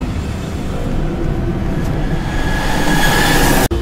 Horror Huggy Wuggy Sound Effect Free Download